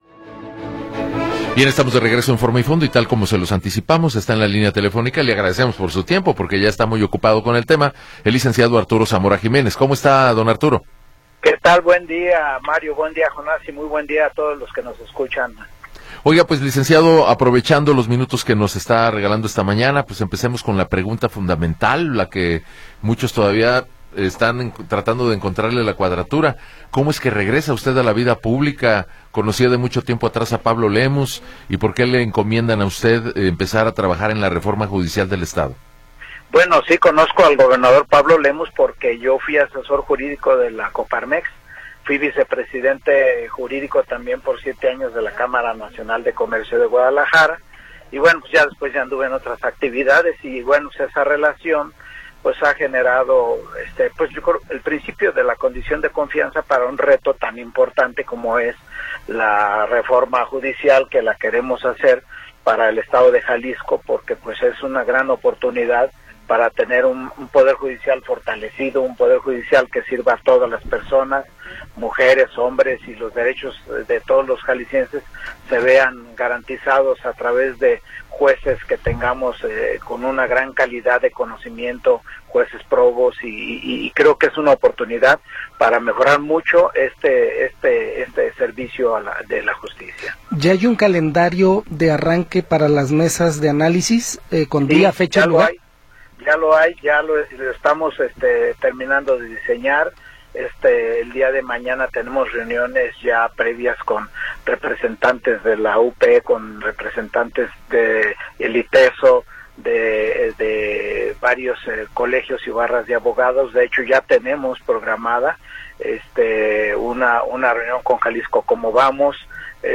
Entrevista con Arturo Zamora Jiménez